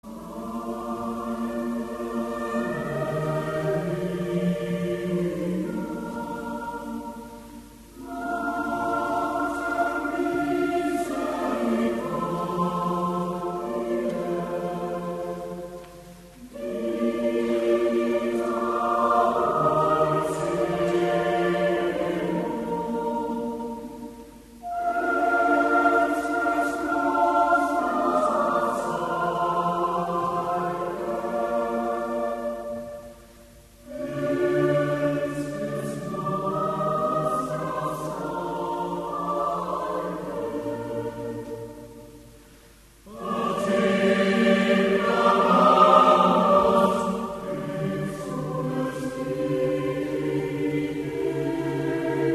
Kirchenkonzert von Kirchenchor Fuchsmühl und Salonorchester mit Liveaufnahmen
Wallfahrtskirche Maria Hilf Fuchsmühl Gemeinsames Konzert von Kirchenchor und Salonorchester.
Salve Regina 4-stg. Chor a cap.
Solisten und Chor der Wallfahrtskirche Maria Hilf Fuchsmühl